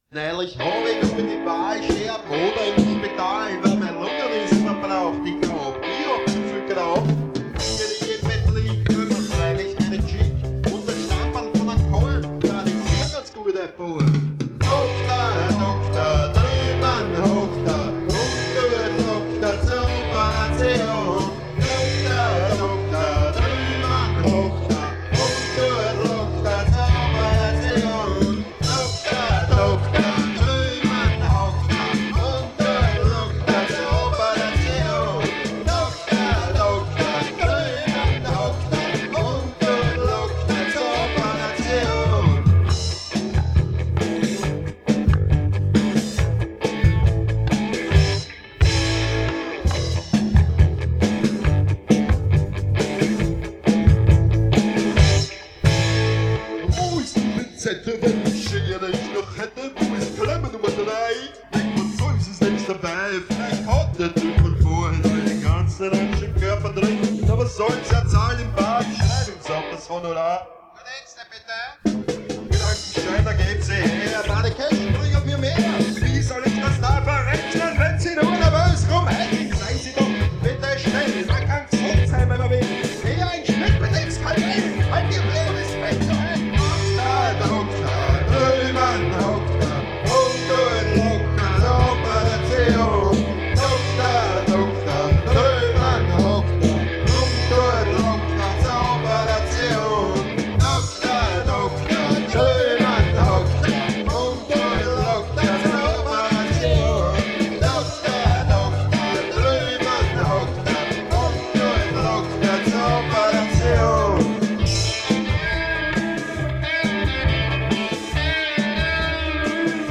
Genre:   Freie Musik - Pop
Schlagzeug
Gitarre
Geige
Gesang